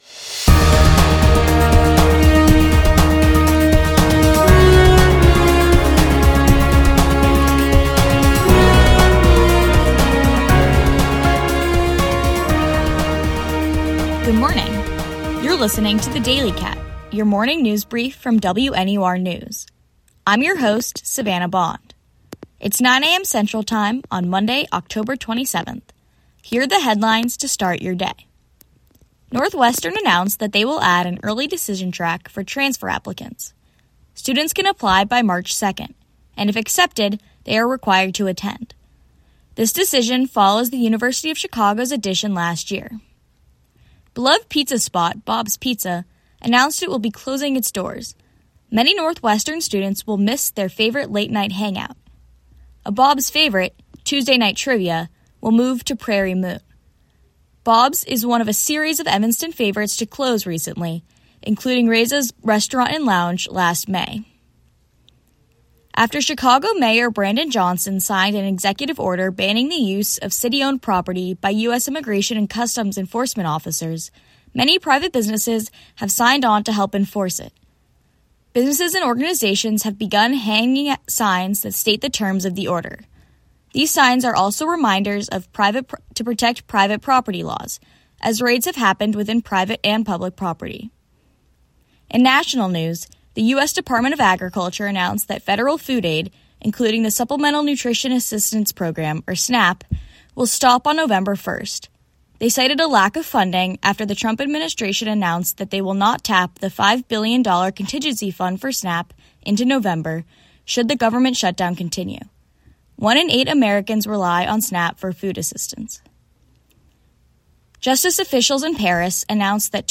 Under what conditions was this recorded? October 27, 2025: Transfer Early Decision, Bob’s Pizza Closes, Local Businesses post no ICE signage, Federal Food Assistance stops 11/1, Louvre heist suspects arrested. WNUR News broadcasts live at 6 pm CST on Mondays, Wednesdays, and Fridays on WNUR 89.3 FM.